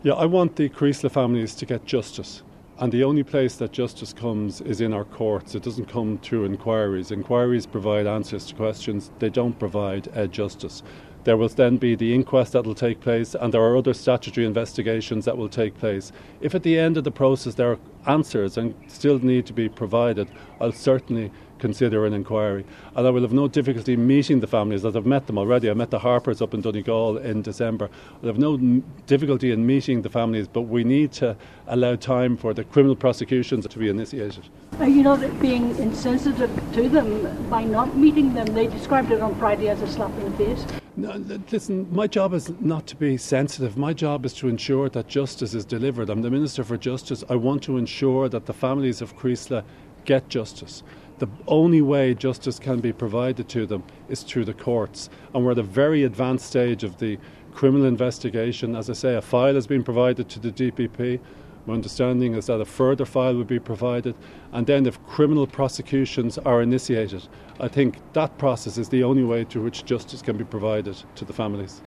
Speaking to reporters in Cavan at a cross-border policing event, Minister Jim O’Callaghan acknowledged the families deserve answers, but repeated his belief that inquiries are not the way to achieve that.